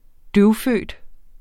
Udtale [ ˈdøw- ]